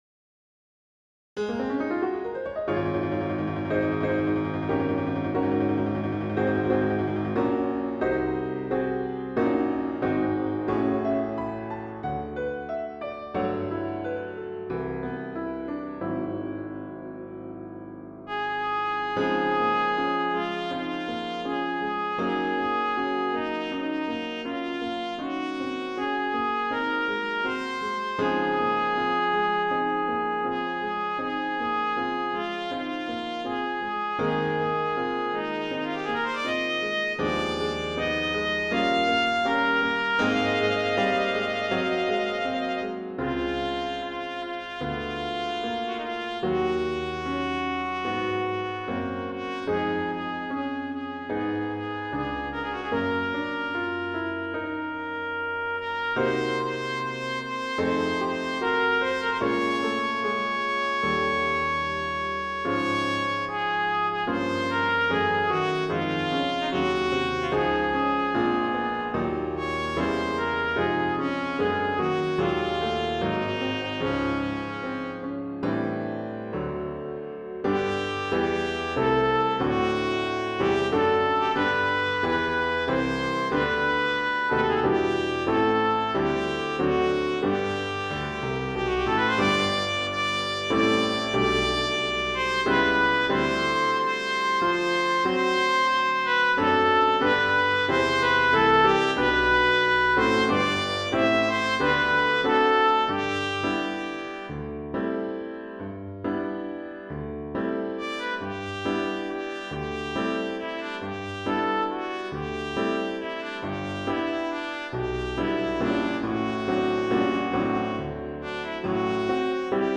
Voicing: Trumpet Solo